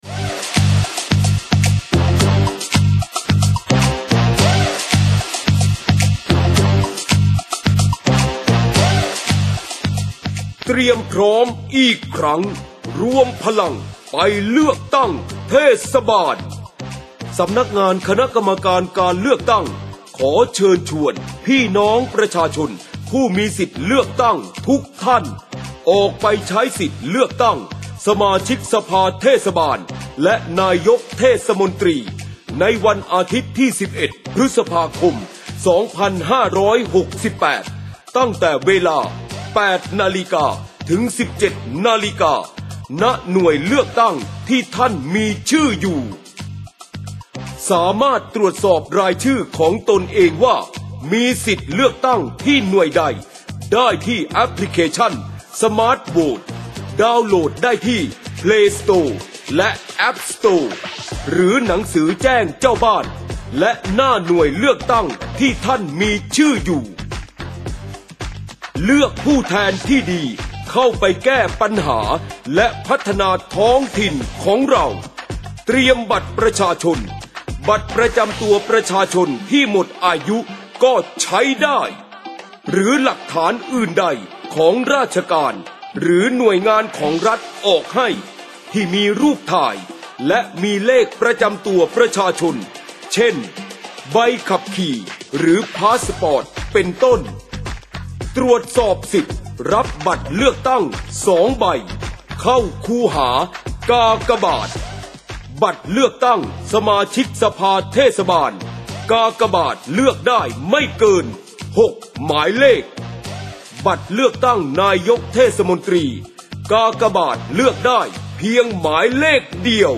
สปอตวิทยุประชาสัมพันธ์ เชิญชวนประชาชนออกมาใช้สิทธิเลือกตั้งสมาชิกสภาเทศบาลและนายกเทศมนตรี เชิญชวนประชาชนออกมาใช้สิทธิเลือกตั้งสมาชิกสภาเทศบาลและนายกเทศมนตรี ดาวน์โหลดไฟล์ด้านล่าง : ดาวน์โหลดไฟล์แนบ เข้าชม : 17